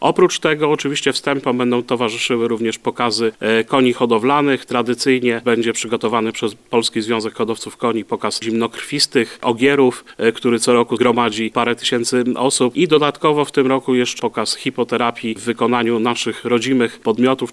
To nie jedyne atrakcje podczas tego wyjątkowego wydarzenia: mówi burmistrz Miasta i Gminy Skaryszew Dariusz Piątek: